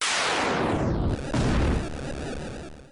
Explosion.mp3